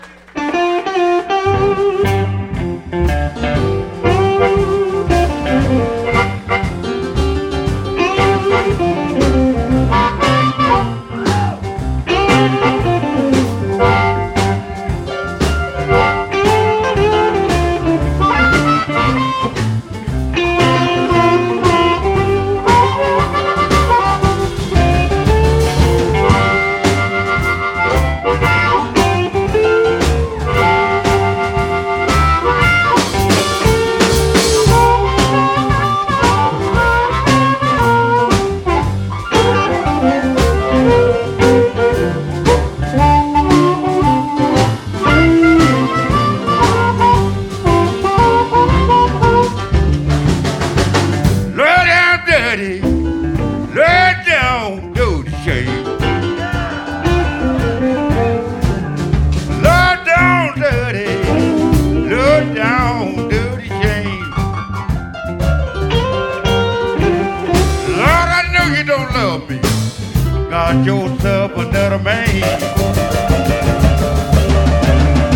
a two disc fully live CD recorded in Santa Cruz, California